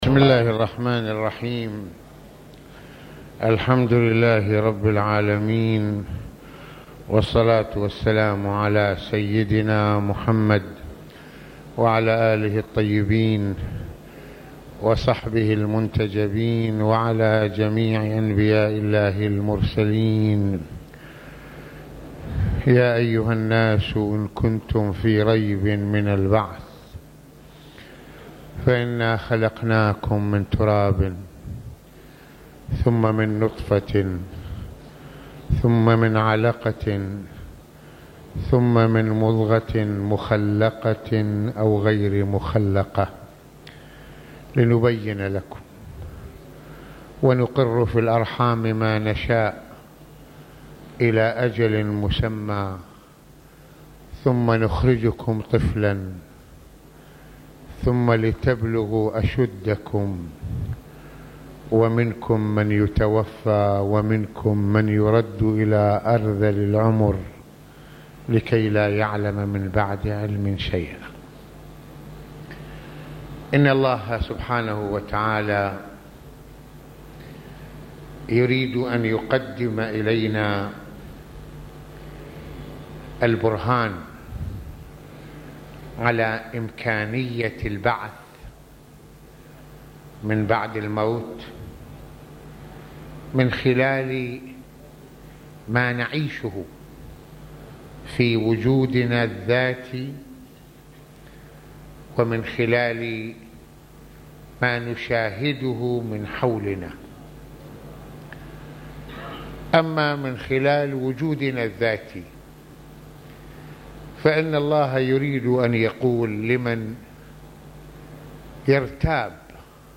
- يتحدّث العلامة المرجع السيّد محمّد حسين فضل الله(رض) في هذه المحاضرة عن برهنة الله تعالى لنا قصة البعث بعد الفناء من خلال تطوّر نشأتنا من مرحلة العدم الى الحياة والله تعالى قادر على إعادة الغنسان بعد فنائه وتحّله إلى رفات..